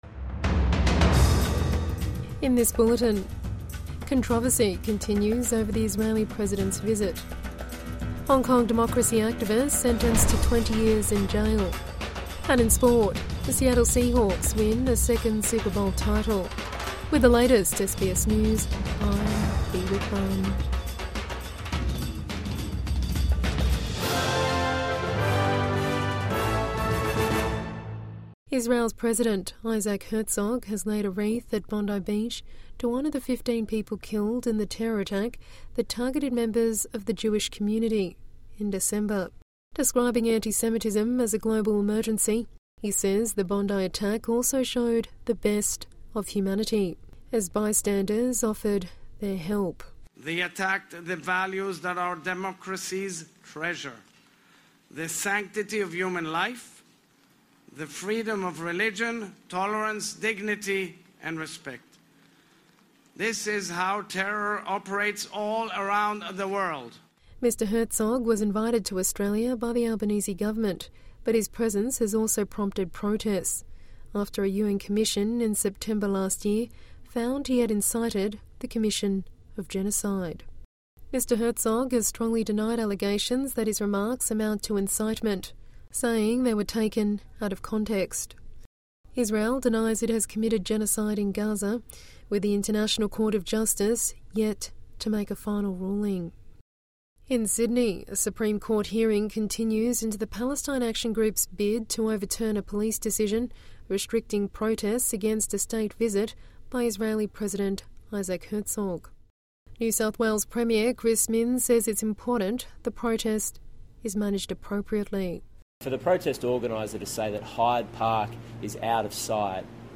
Controversy continues over the Israeli president's visit | Evening News Bulletin 9 February 2026